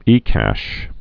(ēkăsh)